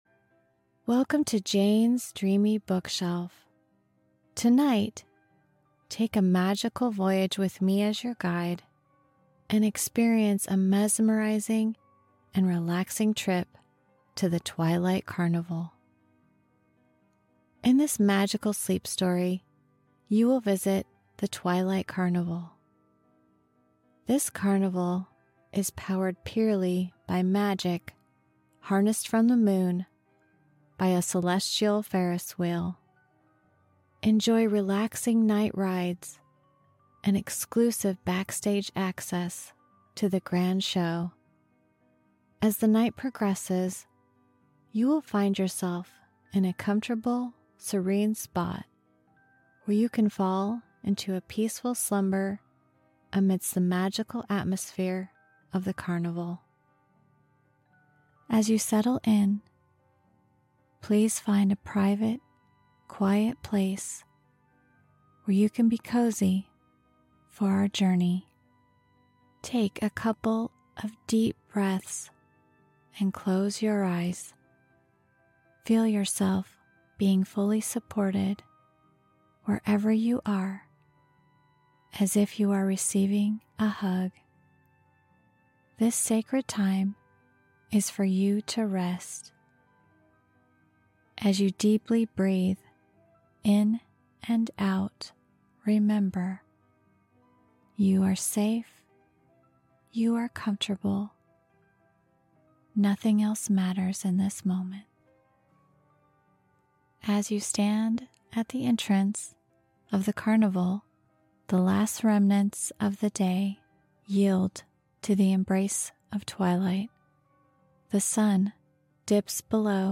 Sleep Story: The Twilight Carnival sound effects free download